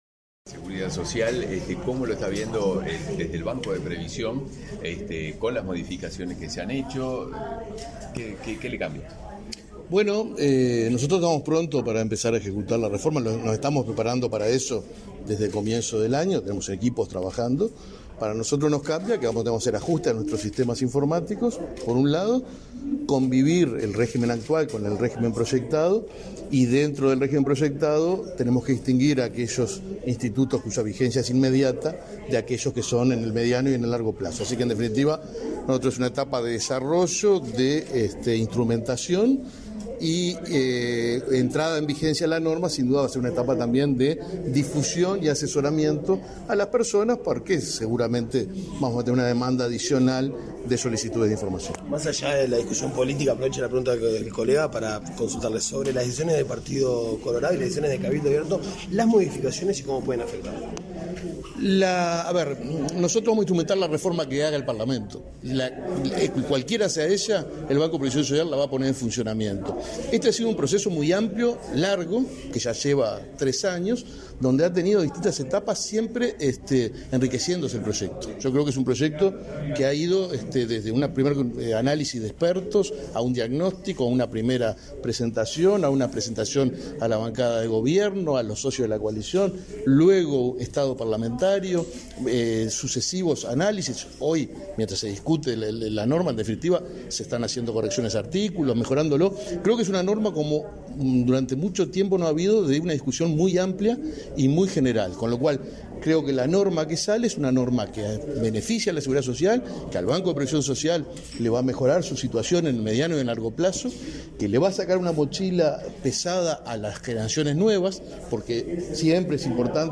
Declaraciones a la prensa del presidente del BPS, Alfredo Cabrera
Declaraciones a la prensa del presidente del BPS, Alfredo Cabrera 24/04/2023 Compartir Facebook X Copiar enlace WhatsApp LinkedIn Tras participar en el acto de presentación de beneficios en las tarifas de consumo de energía eléctrica para hogares de ancianos y usuarios del Programa de Asistencia a la Vejez, este 24 de abril, el presidente del Banco de Previsión Social (BPS), Alfredo Cabrera, realizó declaraciones a la prensa.
cabrera prensa.mp3